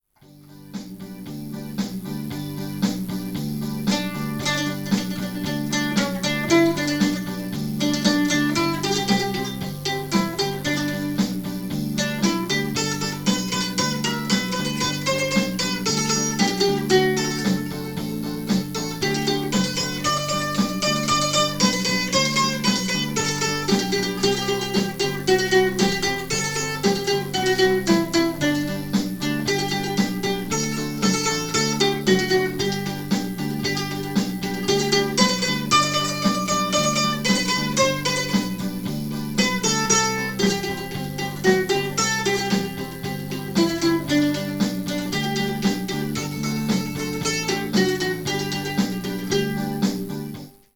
Hopp: egy mandolin!